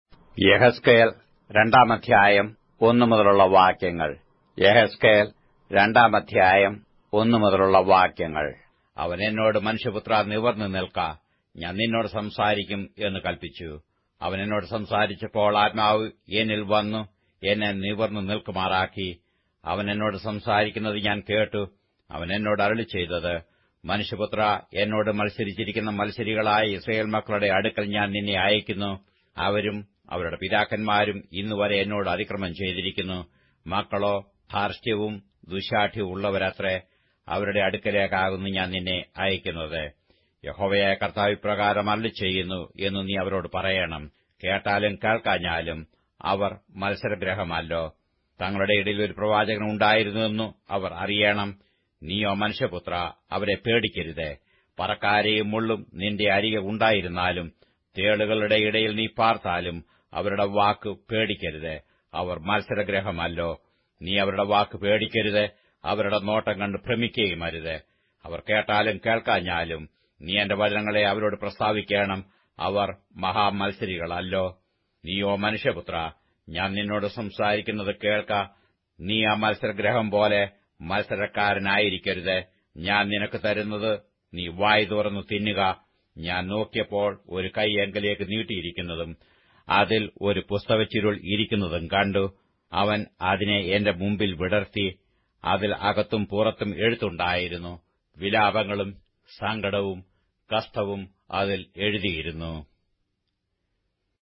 Malayalam Audio Bible - Ezekiel 21 in Bnv bible version